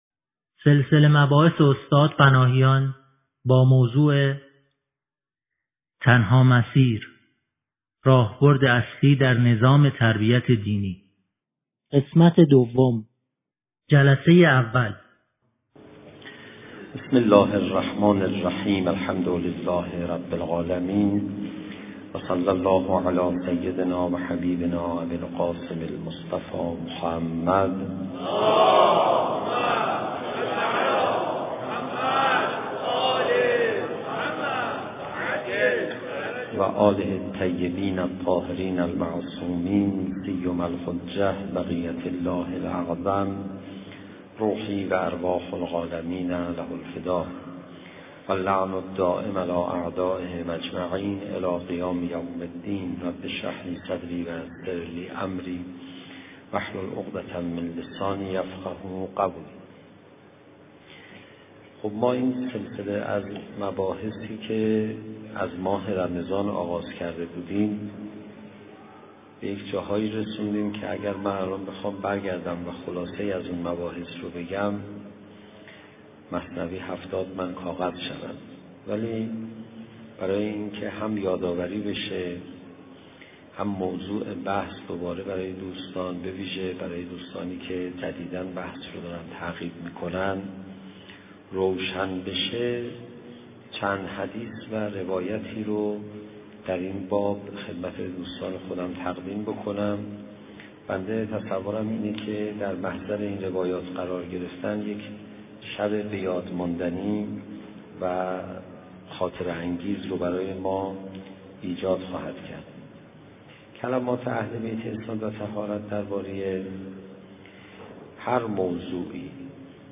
سخنرانی پناهیان-مبارزه با هوای نفس پنهان :: پایگاه مذهبی شهید حججی